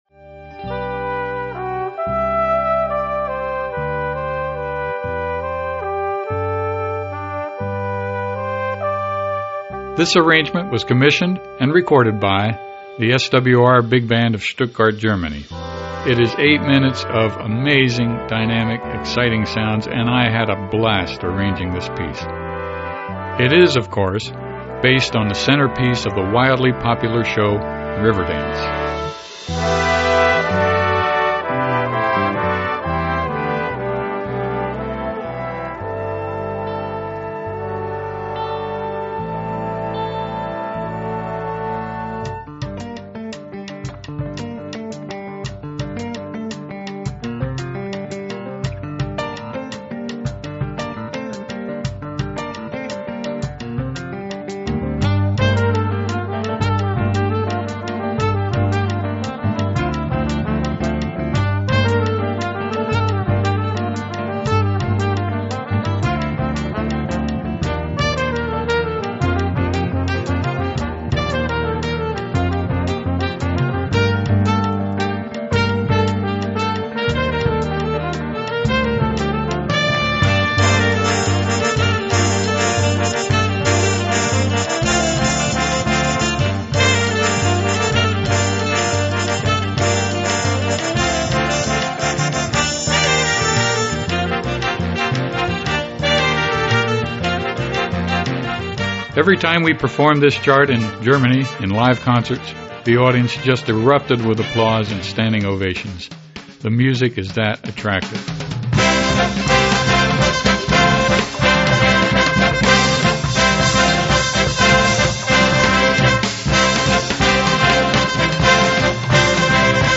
Eight minutes of amazing, dynamic, exciting sounds!